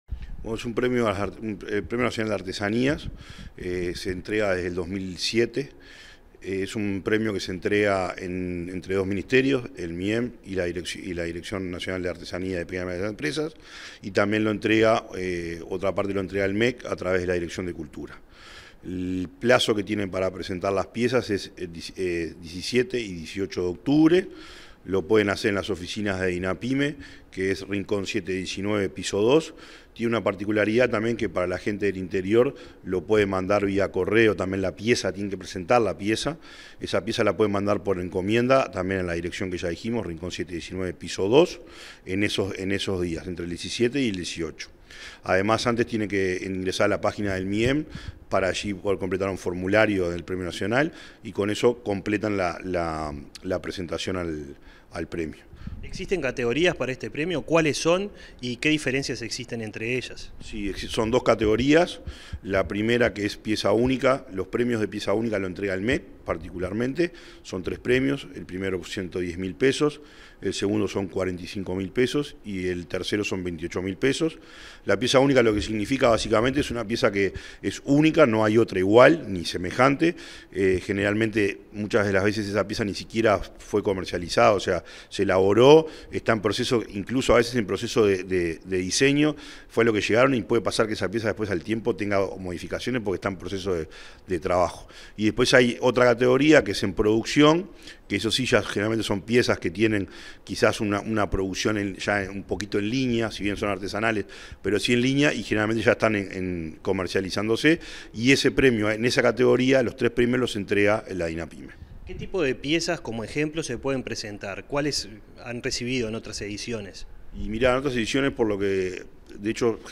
Entrevista al director de Artesanías, Pequeñas y Medianas Empresas, Gonzalo Maciel